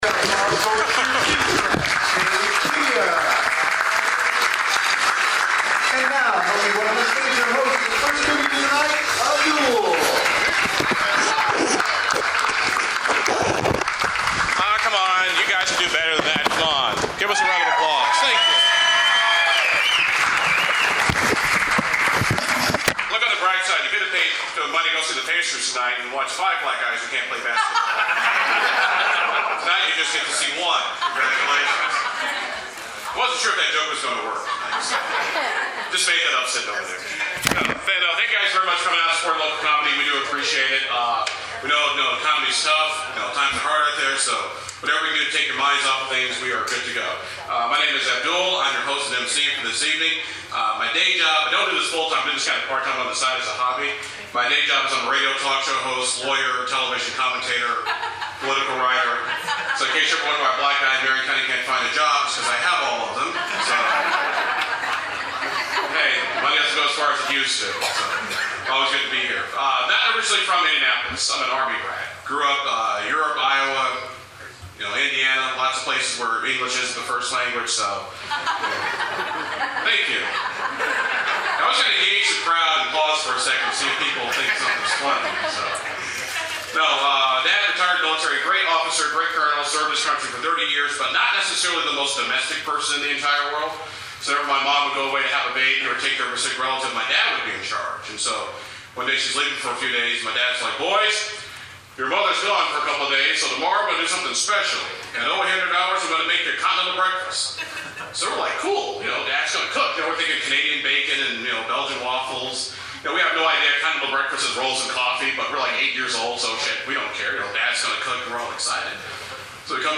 I did a little comedy this past weekend in downtown Indy.
comedy.mp3